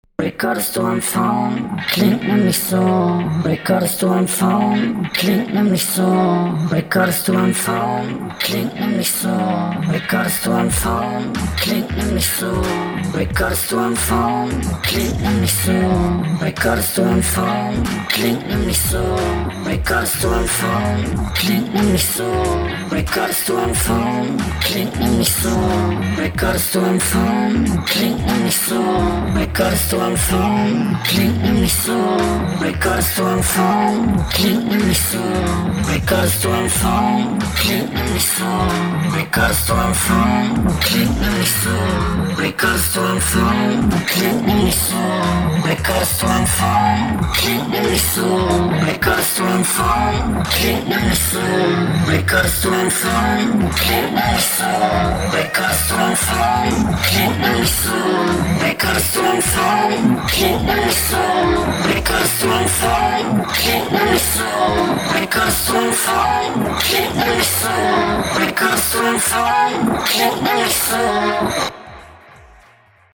Ja was soll man hier sagen, ist nur nur eine Zeile die sich durchgehend wiederholt …
Heftige Battleraprunde, läuft bei mir im Auto.